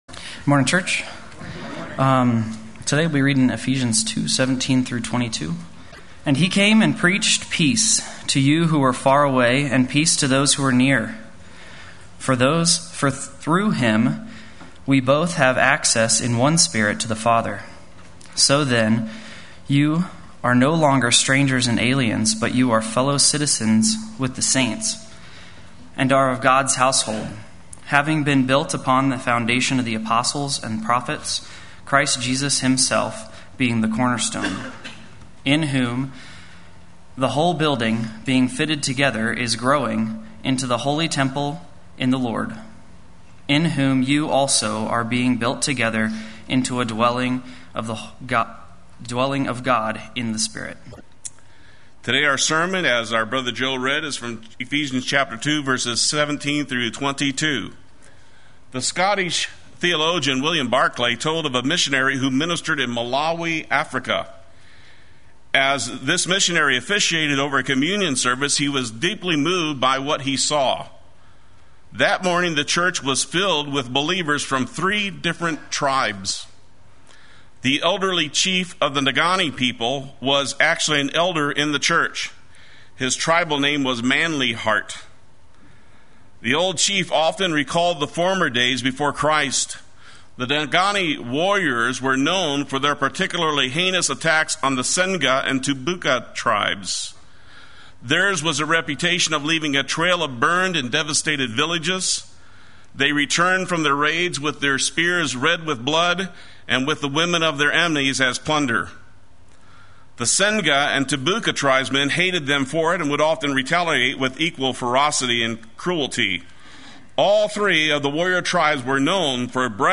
Play Sermon Get HCF Teaching Automatically.
“Through Him We Both” Sunday Worship